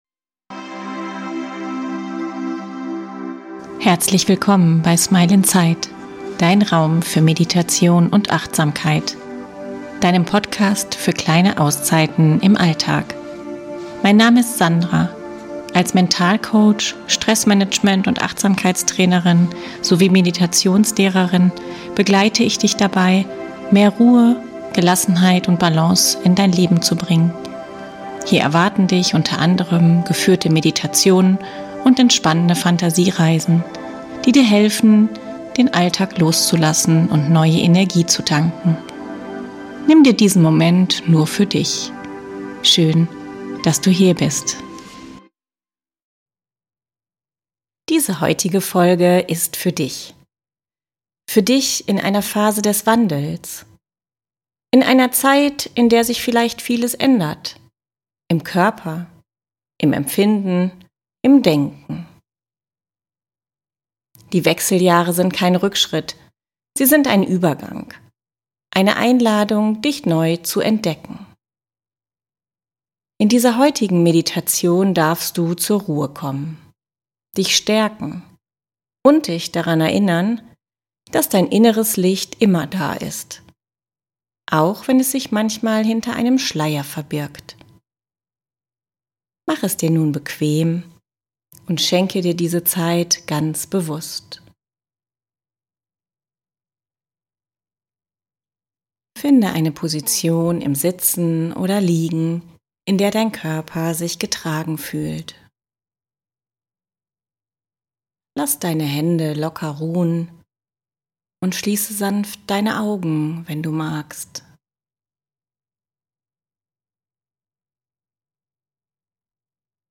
In dieser geführten Meditation lade ich dich ein, zur Ruhe zu kommen und dich wieder mit deiner inneren Stärke zu verbinden. In einer achtsamen Fantasiereise ans Meer begegnest du dem Leuchtturm in dir – einem Symbol für deine Beständigkeit, deine Weisheit und dein inneres Licht, das auch dann leuchtet, wenn sich im Außen vieles bewegt.